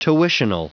Prononciation du mot tuitional en anglais (fichier audio)